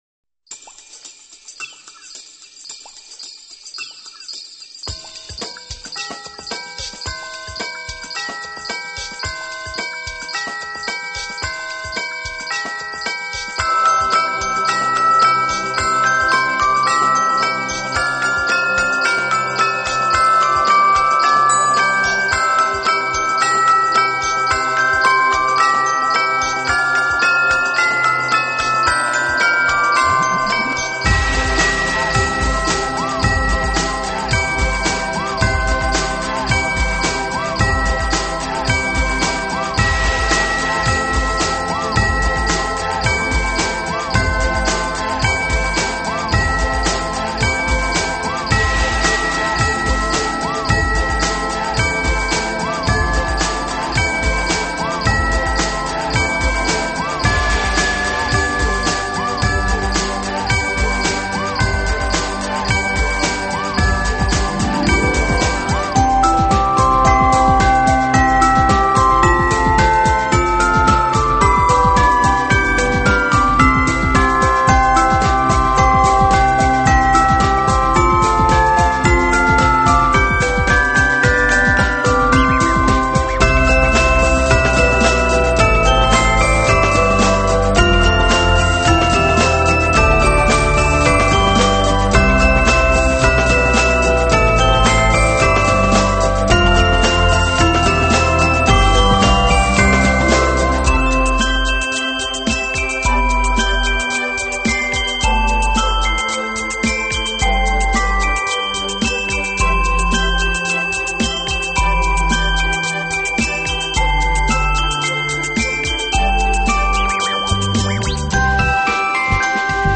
耳熟能详的耶诞歌曲，不一样的编曲演奏，
充满想象的音乐曲风，满足您从小到大的奇幻梦想。
充满惊奇幻想的圣诞舞曲。